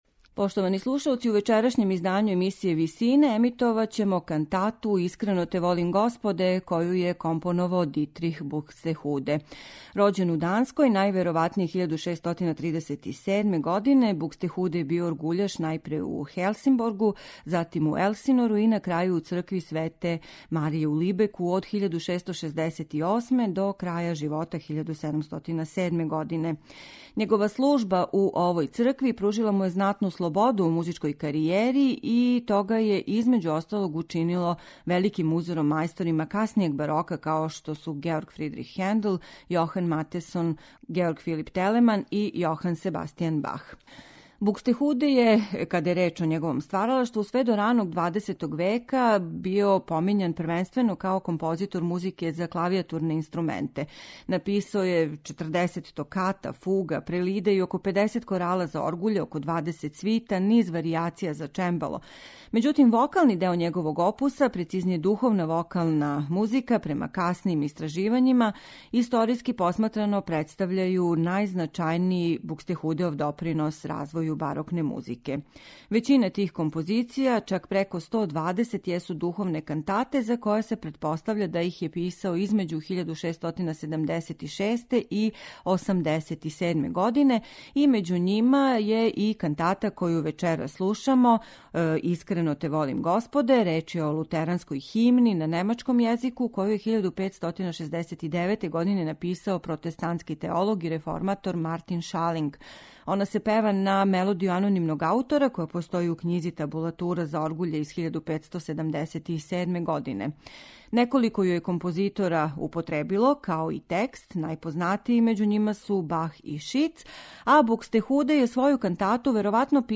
Немачки барокни композитор Дитрих Букстехуде аутор је преко 120 духовних кантата, за које се претпоставља да их је писао између 1676. и 1687. године.